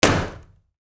Bang.wav